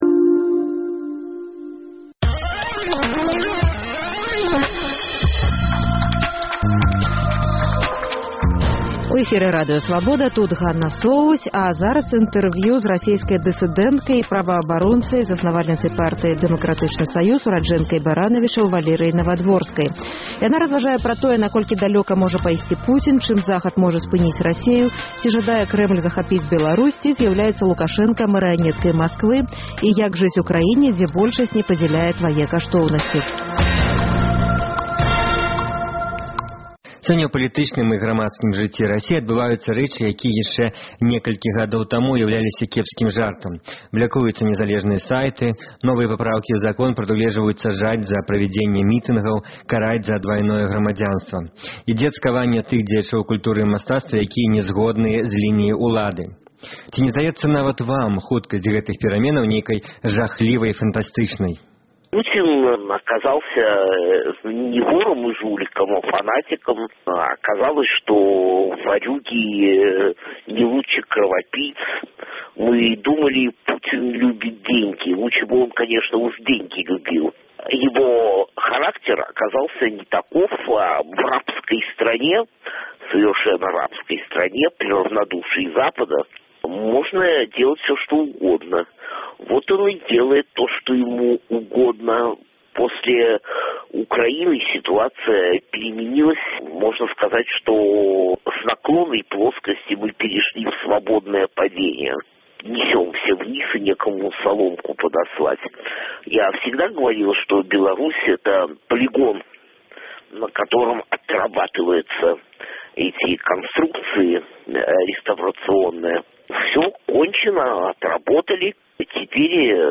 У інтэрвію беларускай Свабодзе расейская дысыдэнтка і праваабаронца, заснавальніца партыі «Дэмакратычны саюз», ураджэнка Баранавічаў Валерыя Навадворская разважае пра тое, наколькі далёка можа пайсьці Пуцін, чым Захад можа спыніць Расею, ці жадае Крэмль захапіць Беларусь, ці зьяўляецца Лукашэнка марыянэткай Масквы, і як жыць у краіне, дзе большасьць не падзяляе твае каштоўнасьці.